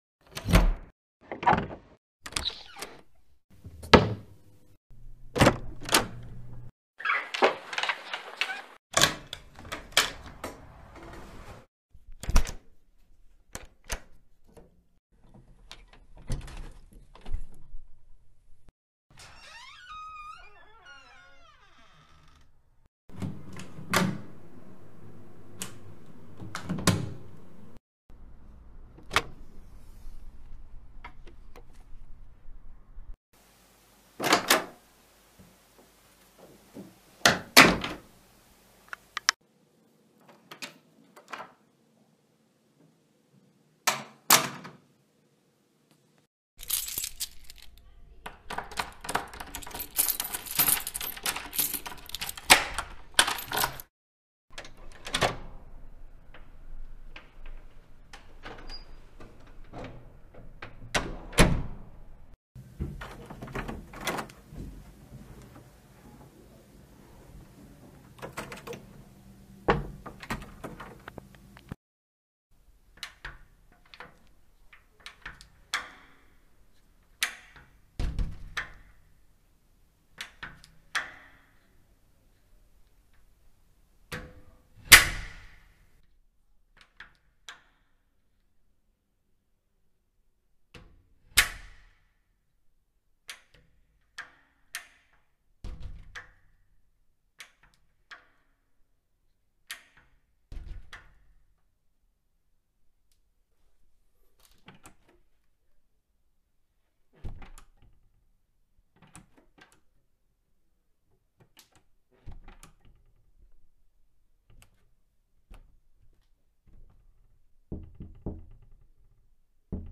دانلود آهنگ باز شدن در از افکت صوتی اشیاء
دانلود صدای باز شدن در از ساعد نیوز با لینک مستقیم و کیفیت بالا
جلوه های صوتی